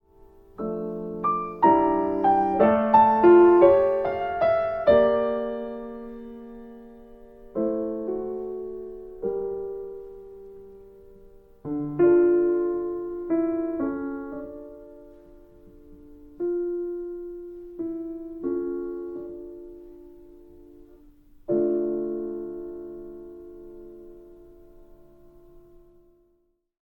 Augmented Triad
Andante, mm.15-18